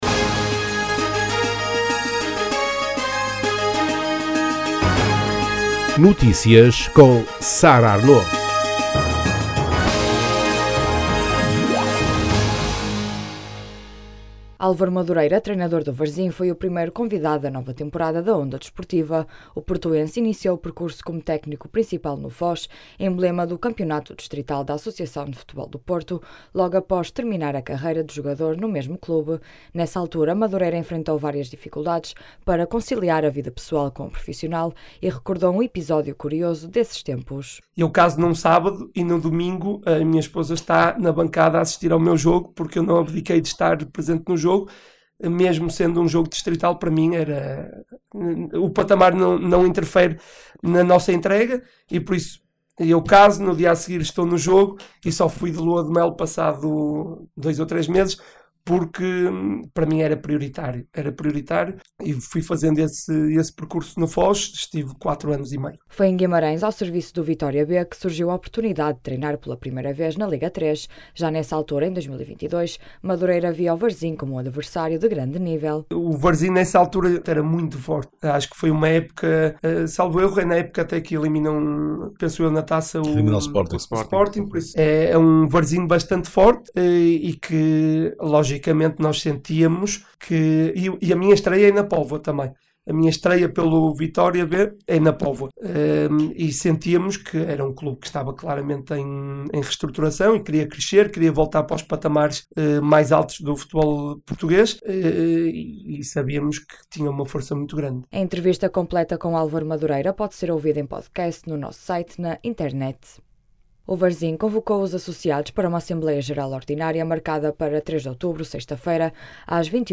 Notícias Regionais